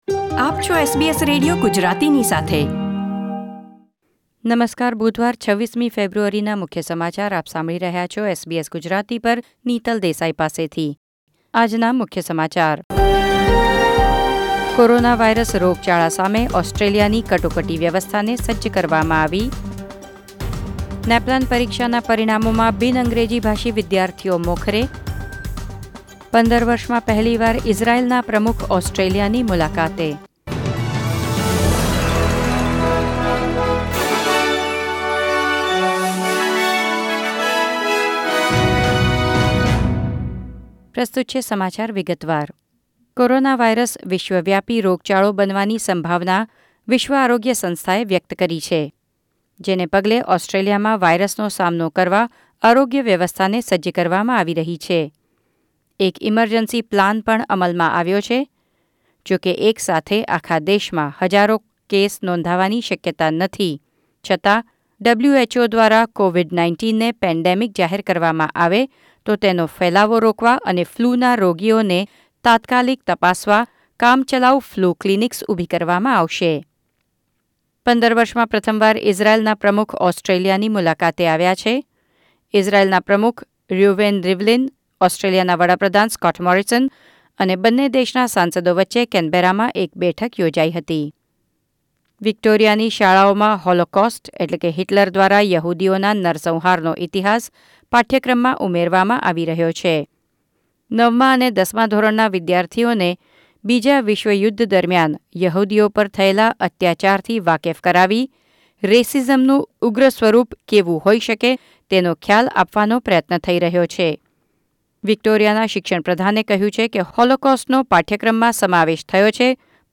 ૨૬ ફેબ્રુઆરી ૨૦૨૦ના મુખ્ય સમાચાર